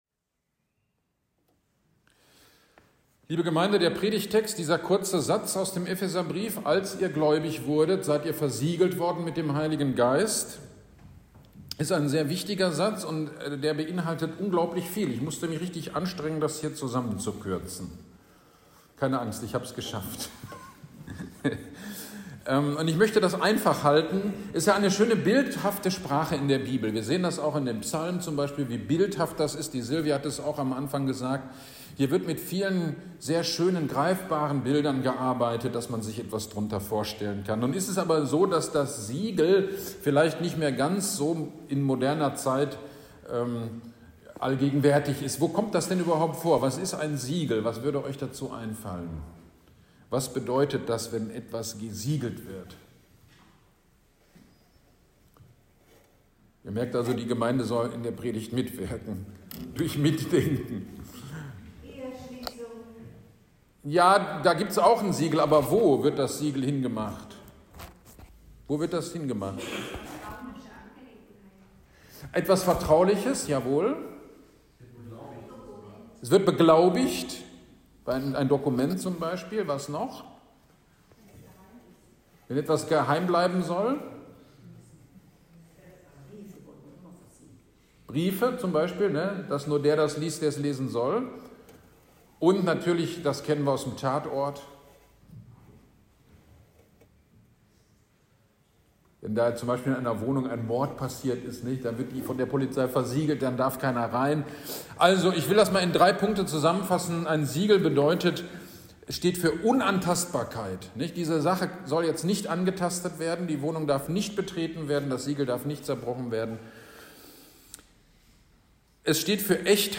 Pfingstgottesdienst am 28.05.23 – Predigt zu Epheser 1.13